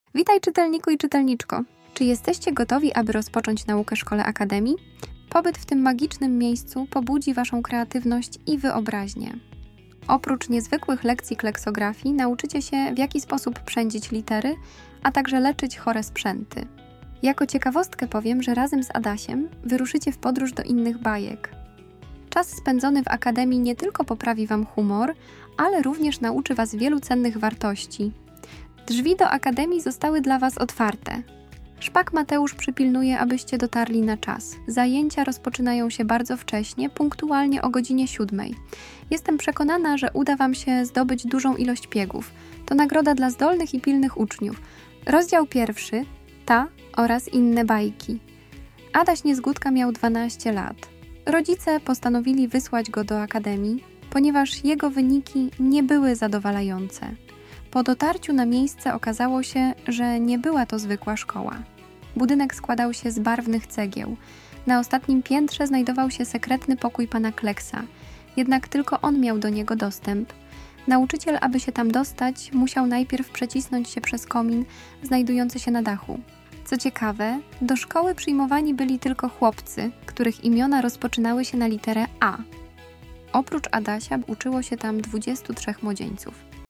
• Szata graficzna i muzyka zachęci Twoje dziecko do zapoznania się z treścią opracowania.
Akademia Pana Kleksa - Prezentacja multimedialna, audiobook, e -book